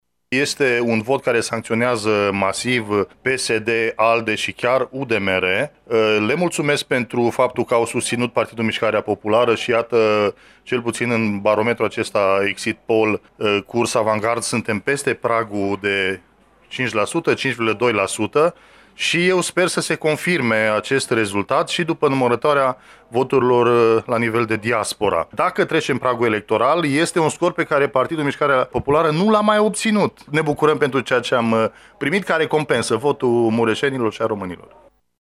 Deputatul PMP de Mureș, Marius Pașcan, e extrem de mulțumit de primele rezultate, care indică faptul că partidul din care face parte a trecut pragul electoral de 5 %. Este totodată un vot care sancționează masiv partidele aflate la guvernare PSD-ALDE, și chiar UDMR, a spus Marius Pașcan: